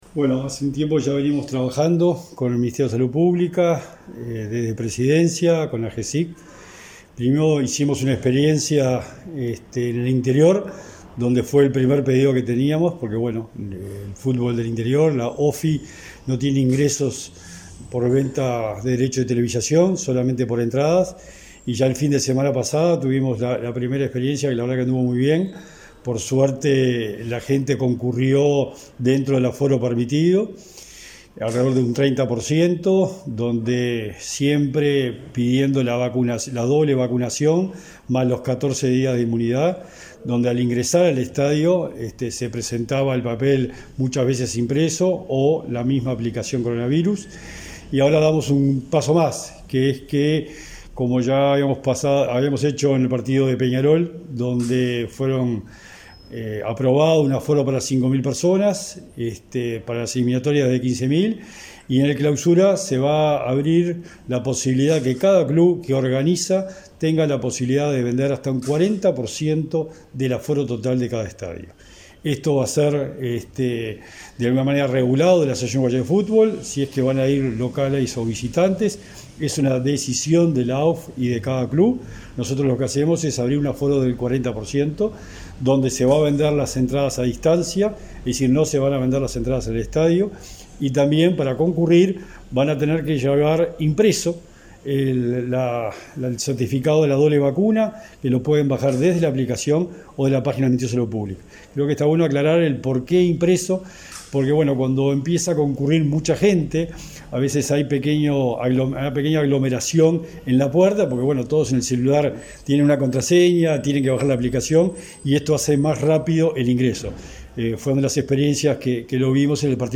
Entrevista al secretario nacional del Deporte, Sebastián Bauzá